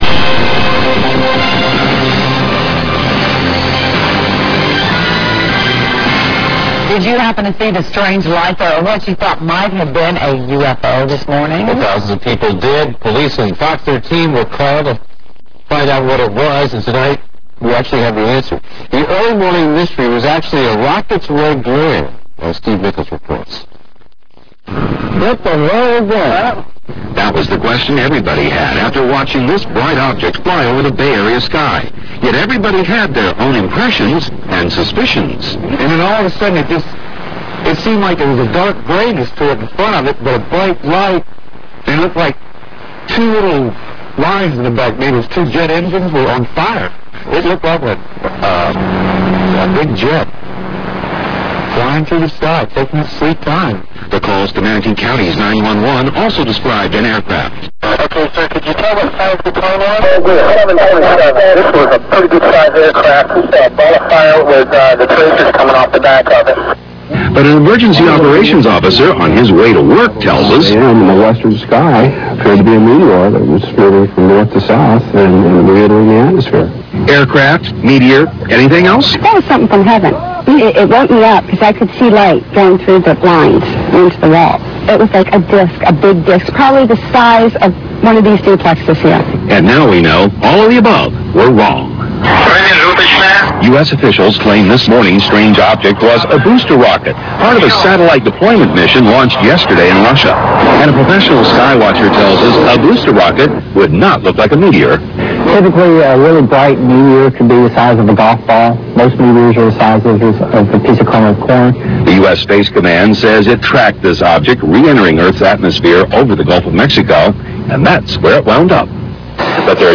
The Fox-13 TV 10PM news report of September 7th was perhaps the best late prime-time news story broadcast in recent memory concerning a UFO sighting. The two news anchors appeared serious and concerned, presenting all sides of the sighting.